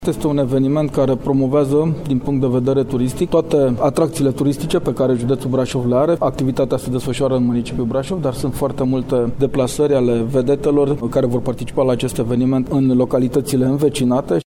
Adrian Veștea, președintele Consiliului Județean Brașov: